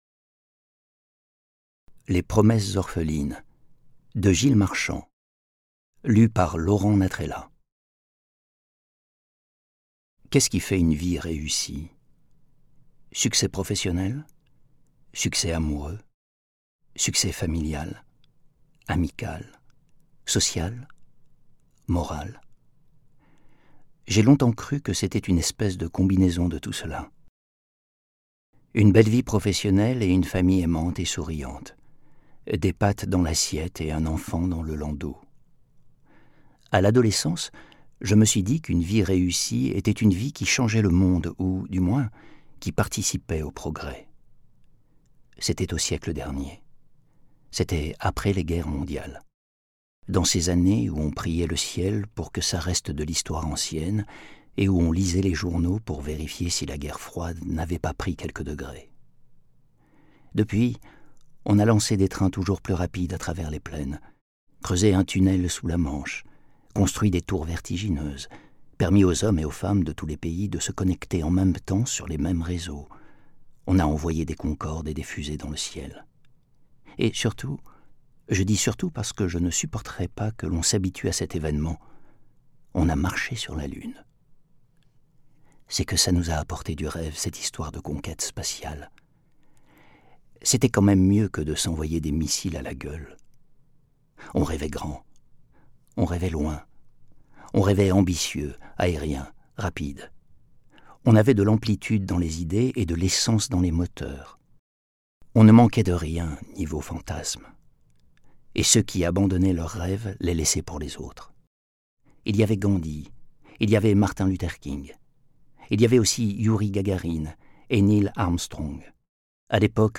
Extrait gratuit - Les Promesses orphelines de Gilles Marchand, Laurent Natrella